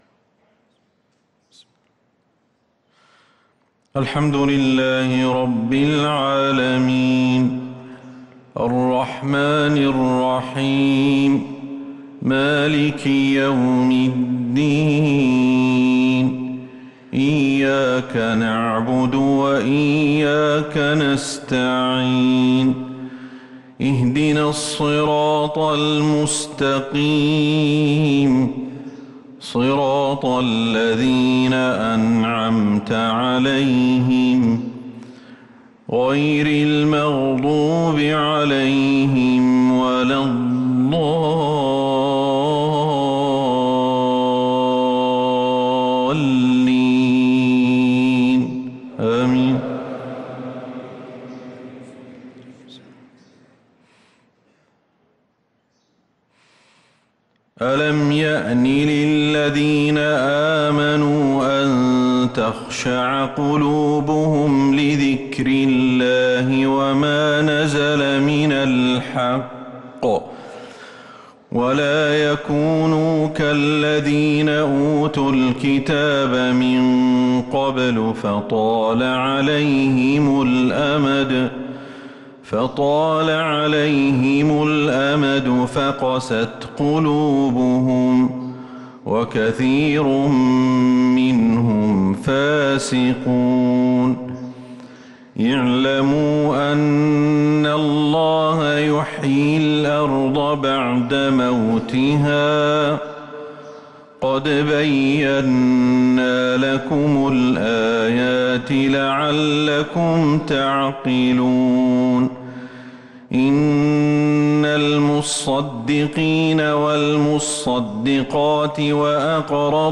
صلاة الفجر للقارئ أحمد الحذيفي 5 ربيع الآخر 1444 هـ
تِلَاوَات الْحَرَمَيْن .